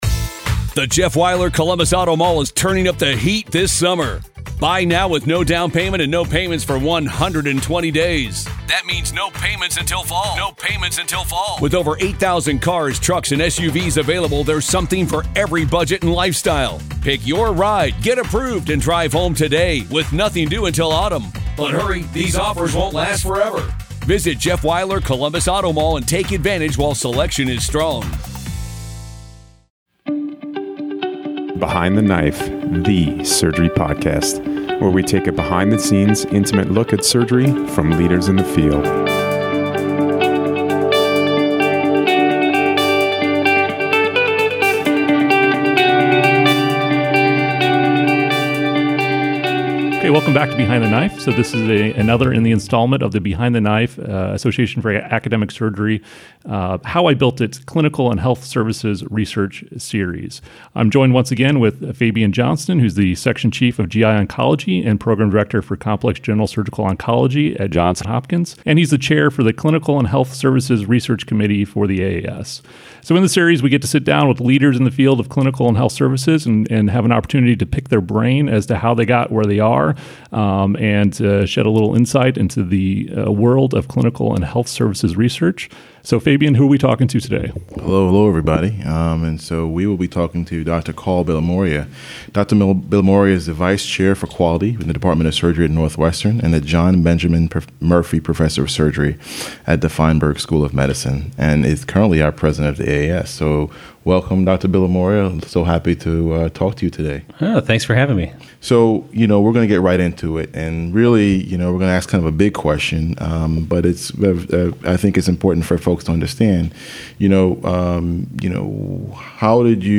Leaders in clinical and health services research discuss keys to success in this collaborative effort between BTK and The Association for Academic Surgery.